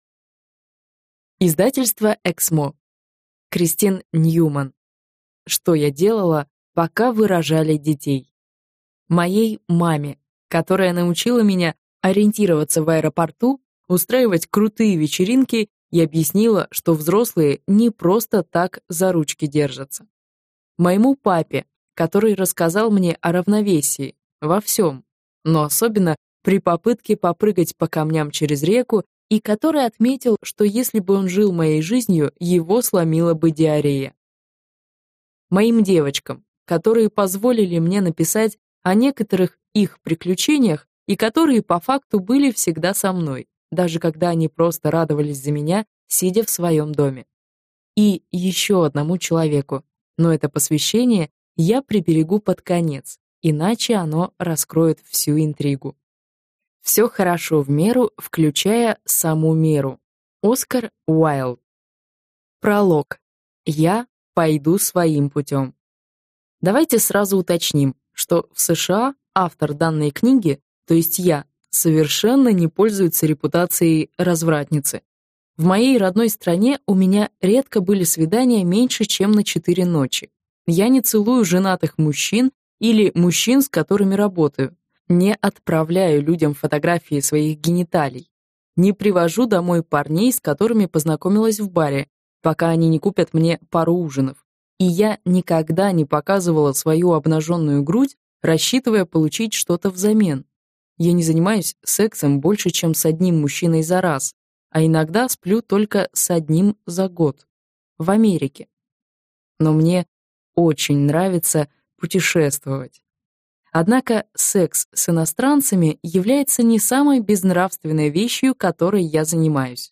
Аудиокнига Что я делала, пока вы рожали детей | Библиотека аудиокниг